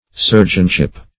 Sergeantship \Ser"geant*ship\, n. The office of sergeant.